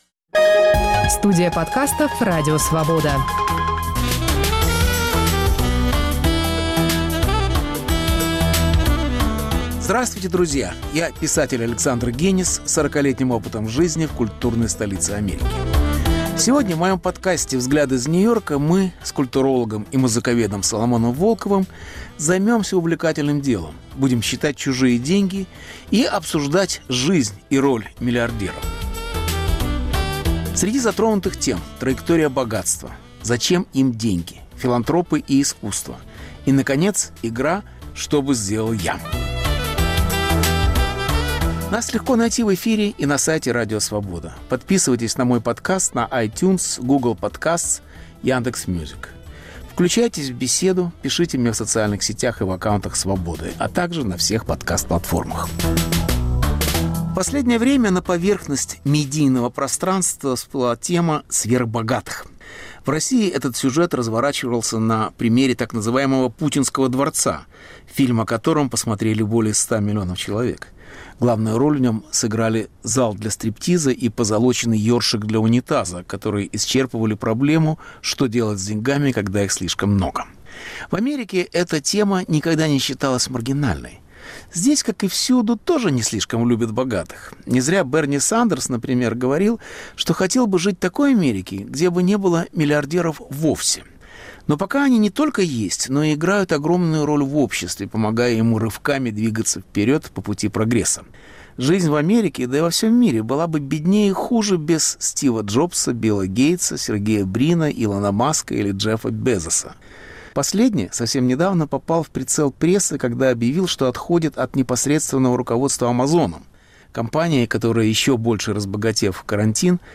Беседа с Соломоном Волковым о филантропах и траектории богатства